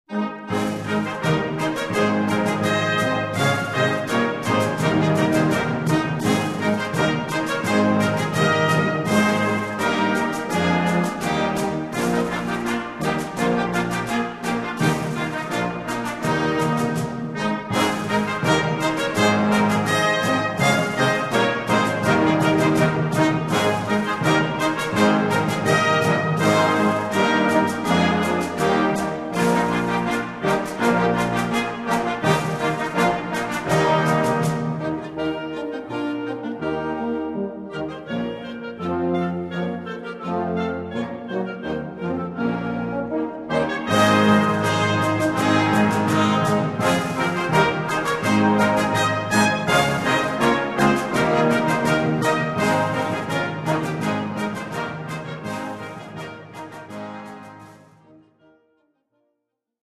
Gattung: Festliche Märsche
Besetzung: Blasorchester